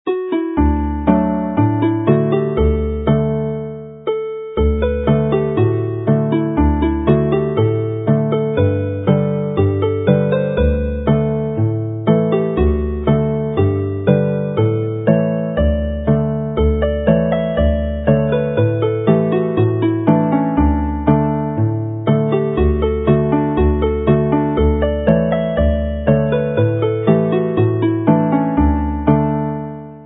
canu + offeryn
in D